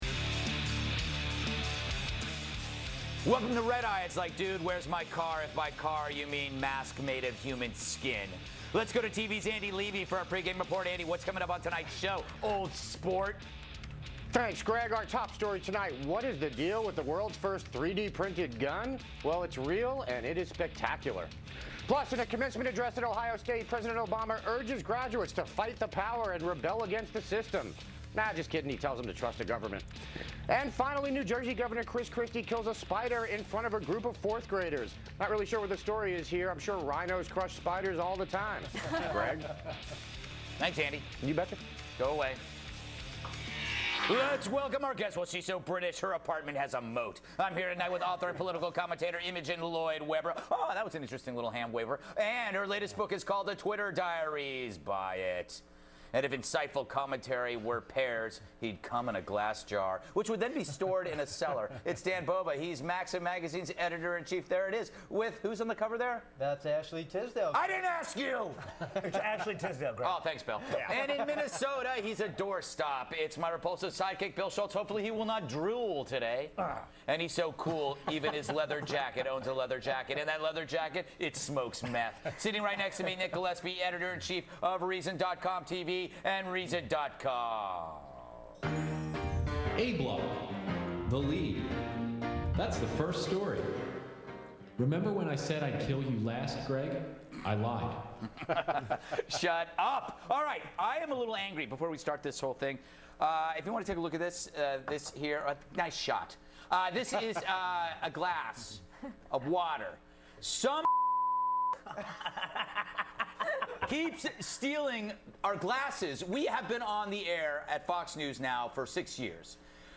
Reason TV Editor-in-Chief Nick Gillespie appeared on Fox's Red Eye w/ Greg Gutfeld to discuss 3-D printed guns, Obama's call to millenials to trust in government, and New Jersey Governor Chris Christie's fight against spiders.